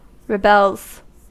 Ääntäminen
Ääntäminen US Haettu sana löytyi näillä lähdekielillä: englanti Käännöksiä ei löytynyt valitulle kohdekielelle. Rebels on sanan rebel monikko.